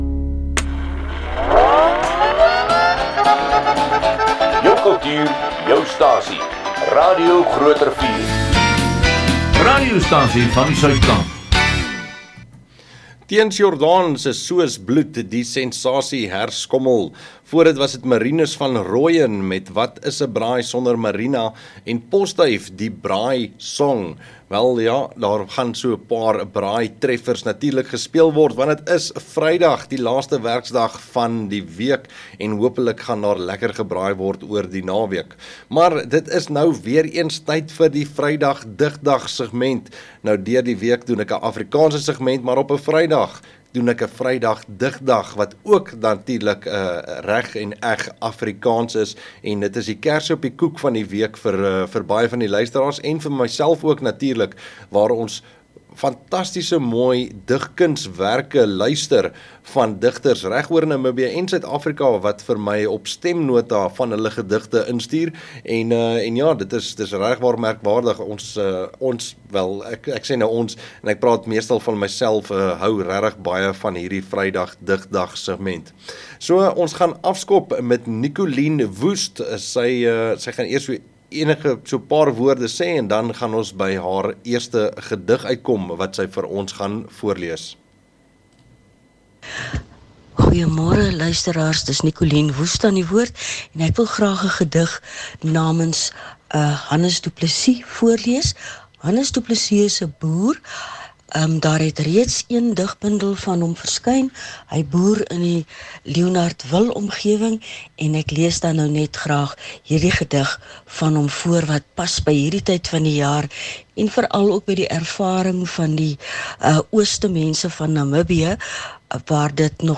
Die Vrydag Digdag is potgooie uit die vrydag afrikaanse sigment in die koffie en kinkel oggend program op Radio Grootrivier. Geniet gedigte voorgelees deur die digters hulself van Namibie en Suid Afrika.